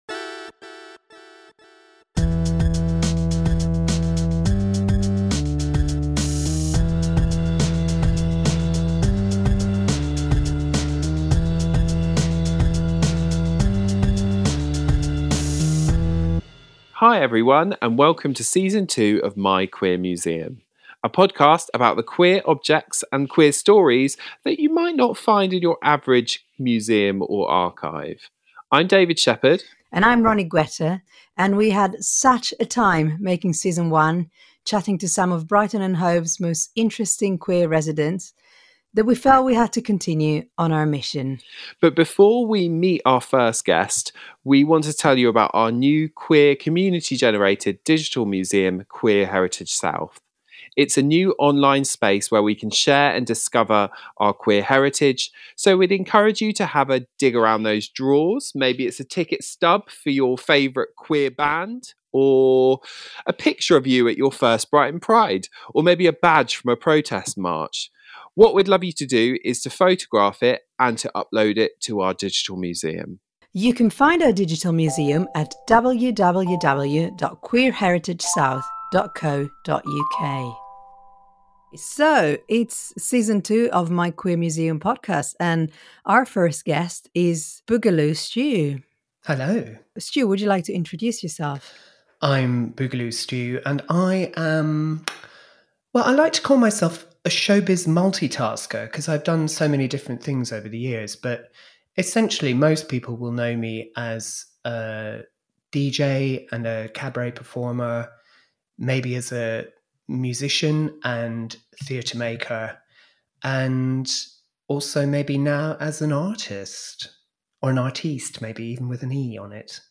Recorded remotely on March 16, 2021, remotely, as part of the My Queer Museum podcast
Oral History